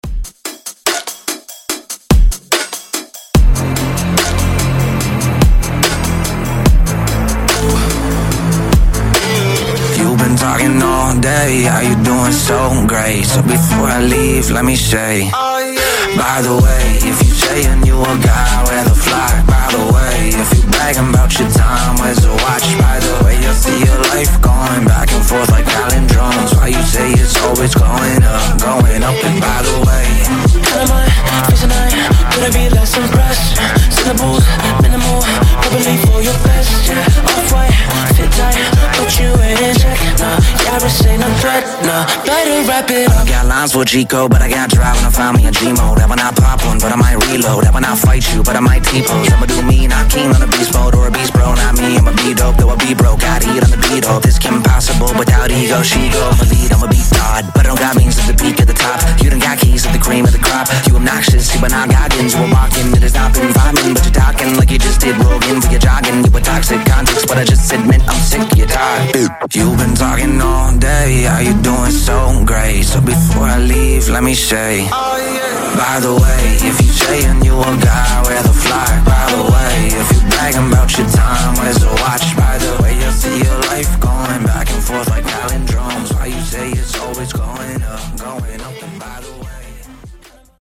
Genre: RE-DRUM
Clean BPM: 143 Time